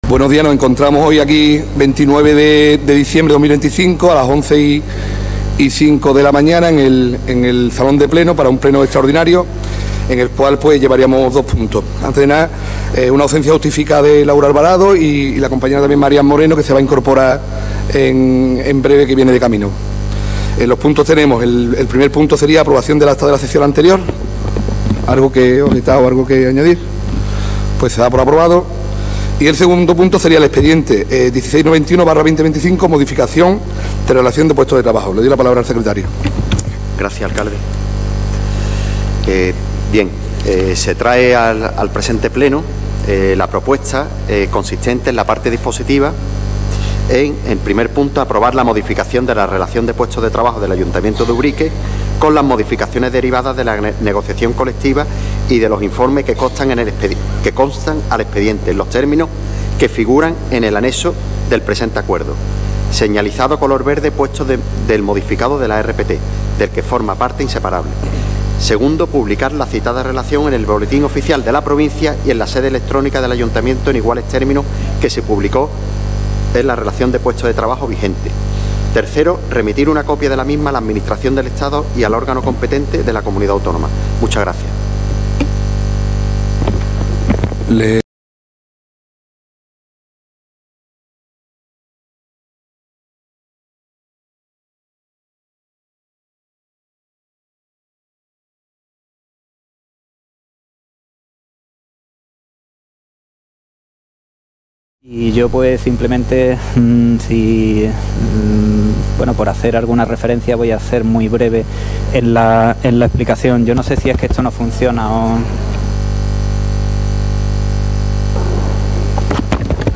Pleno extraordinario 29 de diciembre de 2025